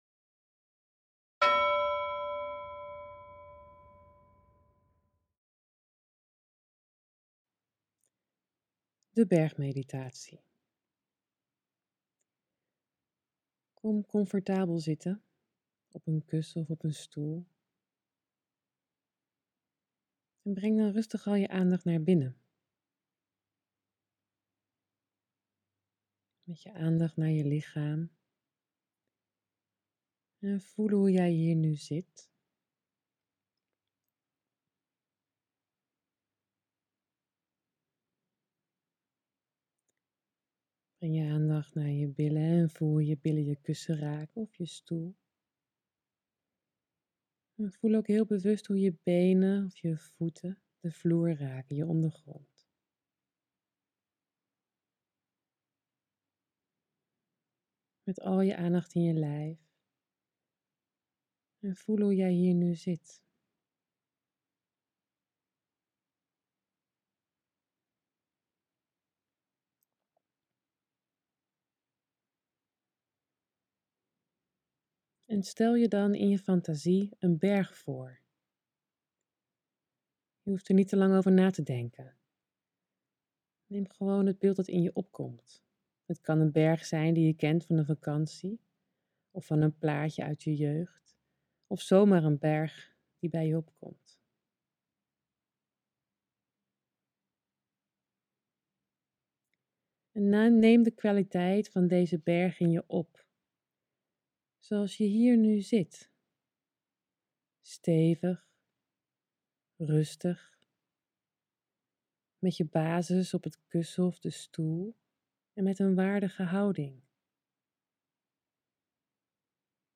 Berg-meditatie
(Je herkent het einde van de meditatie door een gong. Schrik niet 🙂 )